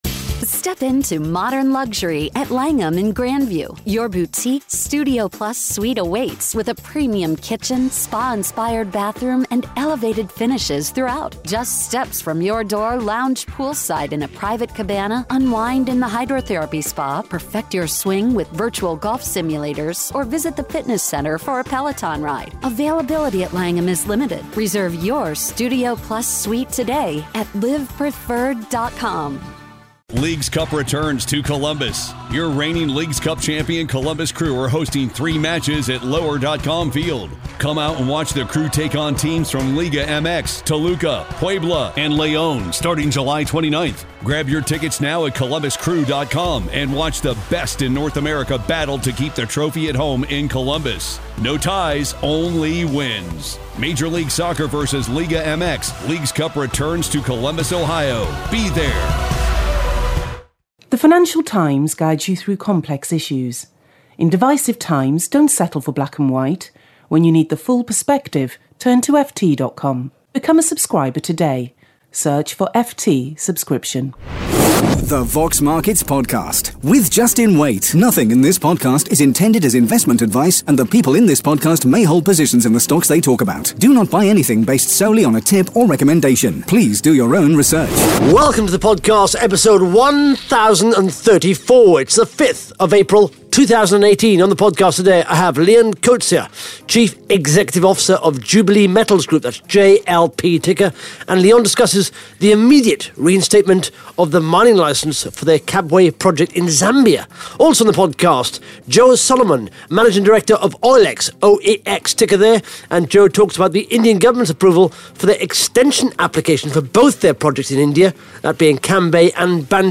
(Interview starts at 1 minute 16 seconds)